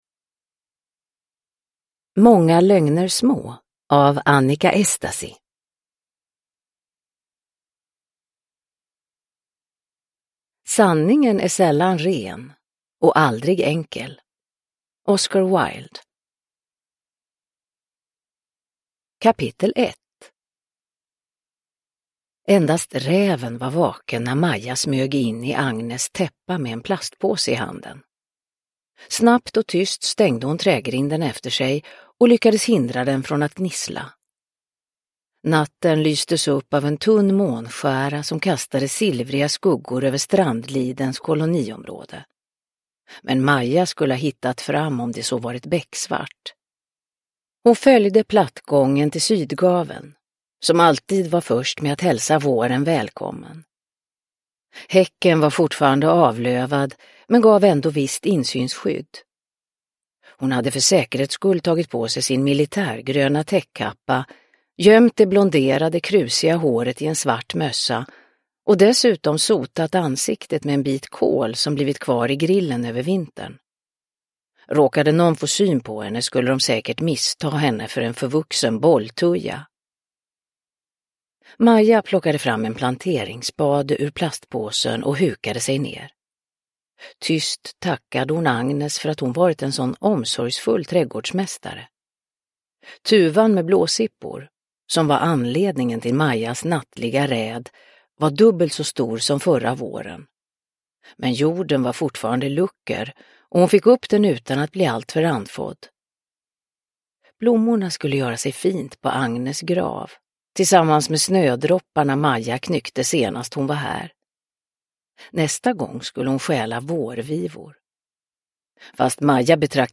Många lögner små – Ljudbok – Laddas ner
Uppläsare: Marie Richardson